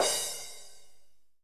002_hccrash.wav